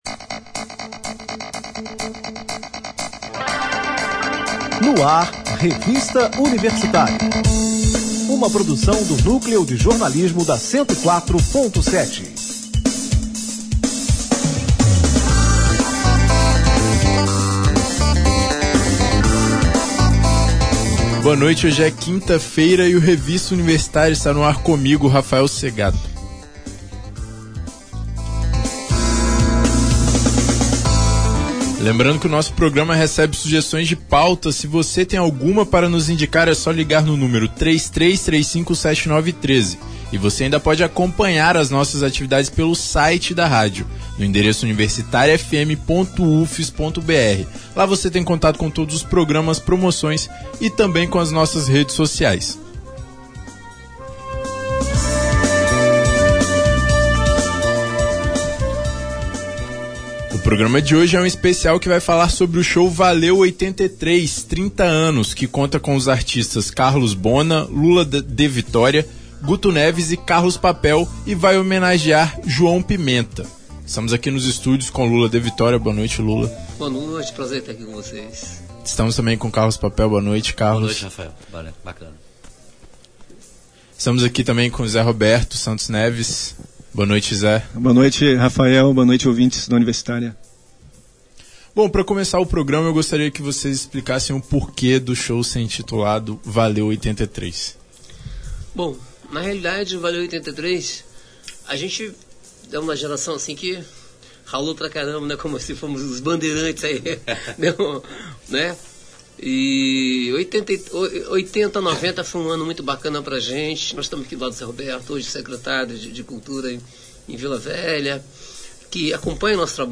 Ouça o bate-papo na íntegra: Revista Universitária especial Download : Revista Universitária especial